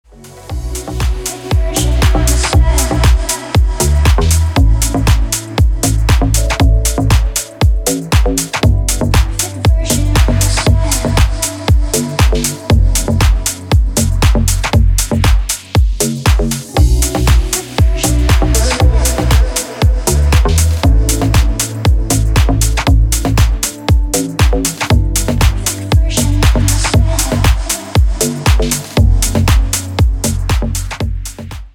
• Качество: 320, Stereo
deep house
Стиль: nu disco, indie dance